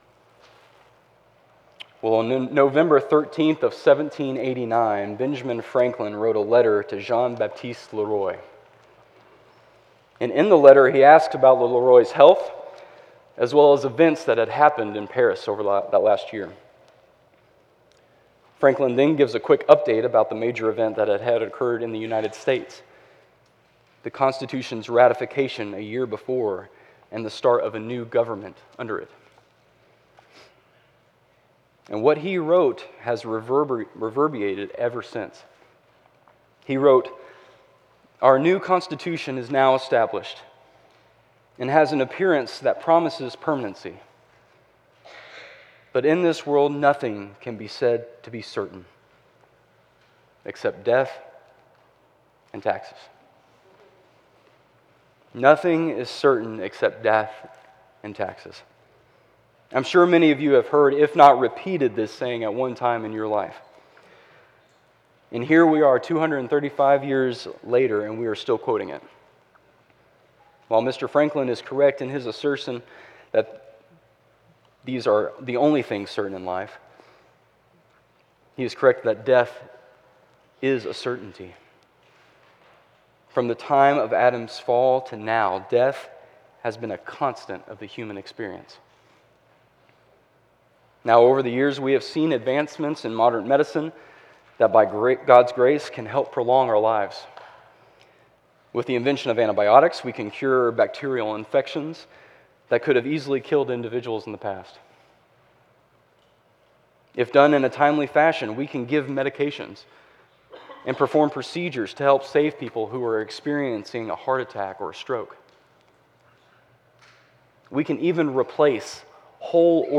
CCBC Sermons